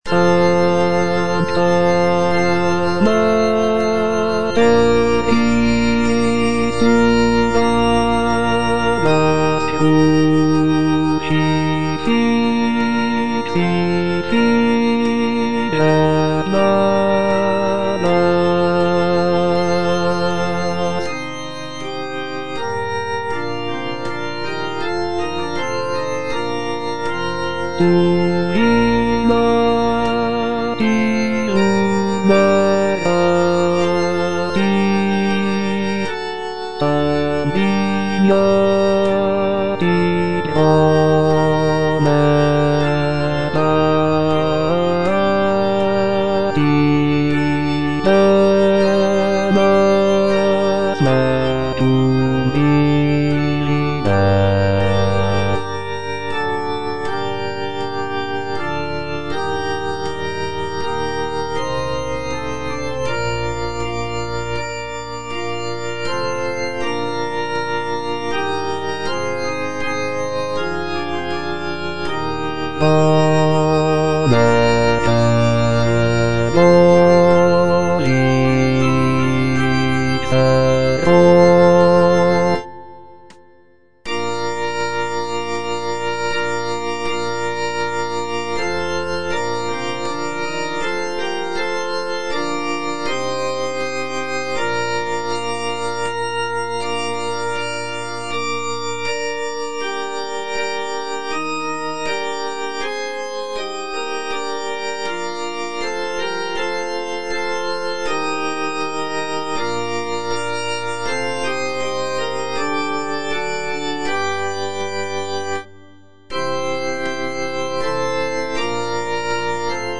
G.P. DA PALESTRINA - STABAT MATER Sancta Mater, istud agas (bass I) (Voice with metronome) Ads stop: auto-stop
is a sacred choral work
Composed in the late 16th century, Palestrina's setting of the Stabat Mater is known for its emotional depth, intricate polyphonic textures, and expressive harmonies.